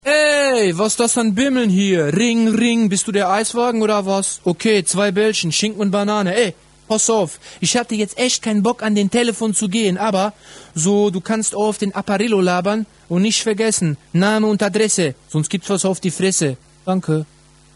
Neu:Ein lustiger Ansagetext im MP3-Format zum